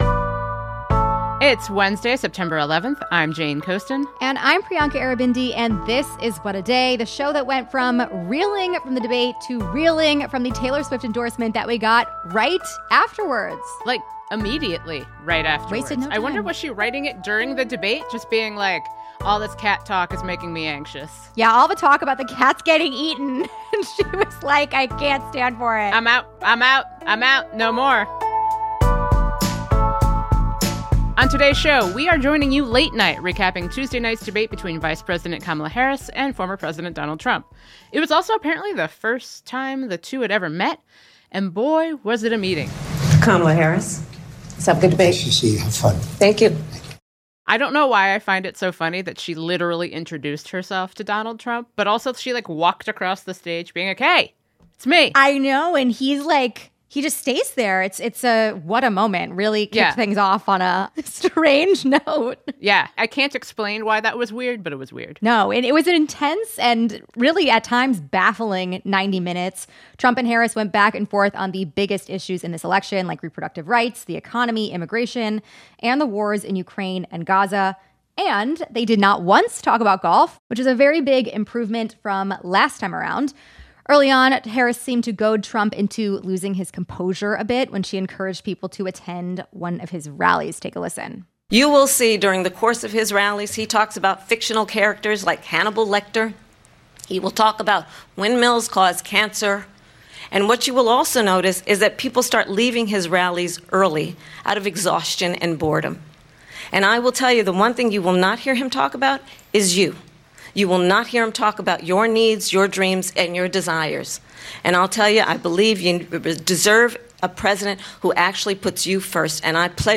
Tommy Vietor, host of Pod Save America and Pod Save the World, joins to give his post-debate analysis.